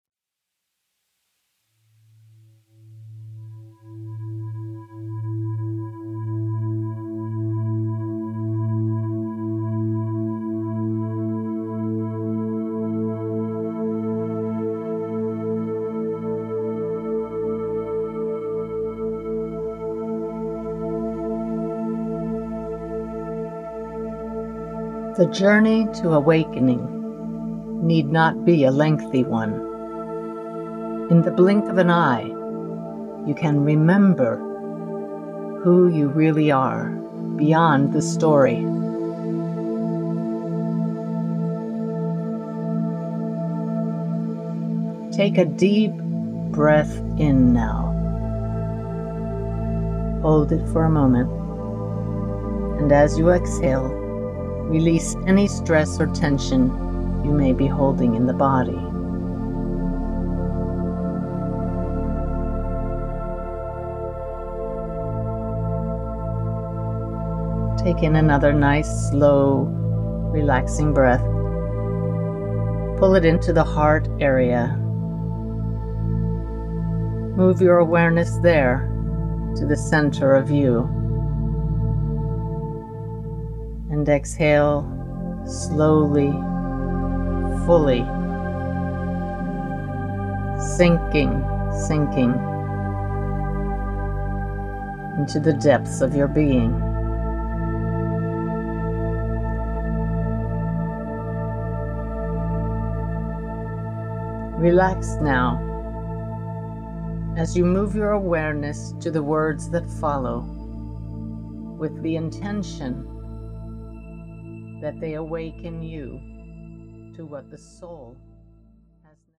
Track 2 is a free-flow experience with a brief introduction to help you achieve the desired expanded state, resting in pure awareness during extended periods of silence.